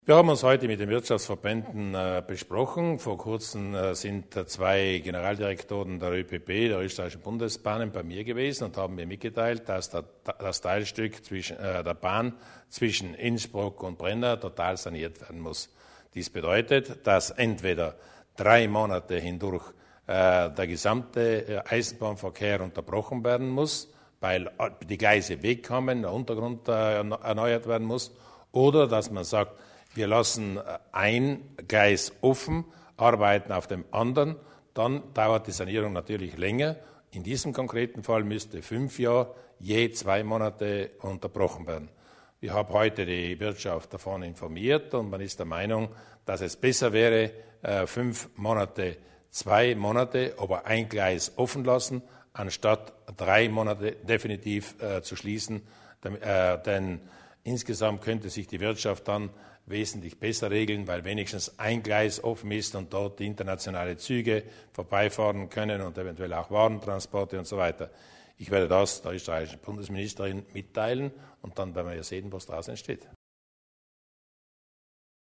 Landeshauptmann Durnwalder zum Treffen mit den Vertretern der Südtiroler Wirtschaftstreibenden